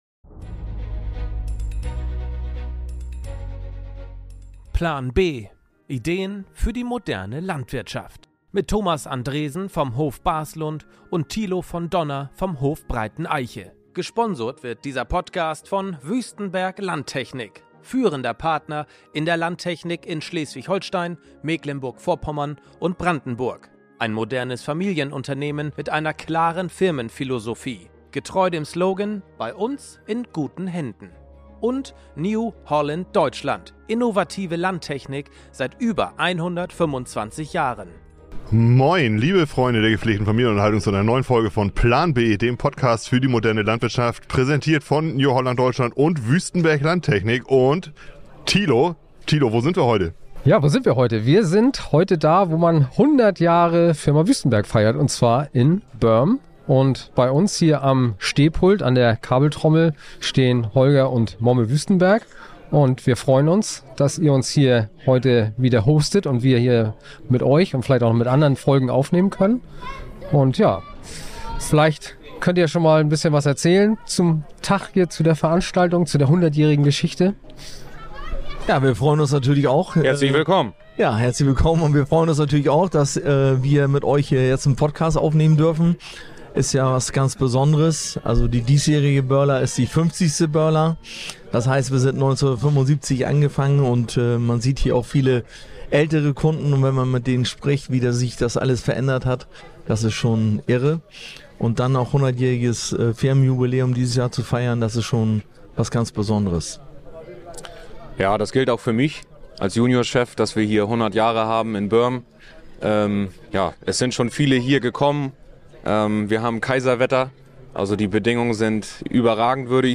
Wie hat sich der Betrieb in einem Jahrhundert verändert? Wie gelingt es, in einem traditionellen Umfeld mutige Innovationen voranzutreiben? Ein Gespräch voller Perspektiven, Leidenschaft und echter Erfahrung – direkt aus Börm, mitten aus dem Herzen der Landwirtschaft.